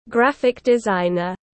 Nghề thiết kế đồ họa tiếng anh gọi là graphic designer, phiên âm tiếng anh đọc là /ˈɡræfɪk dɪˈzaɪnər/.
Graphic designer /ˈɡræfɪk dɪˈzaɪnər/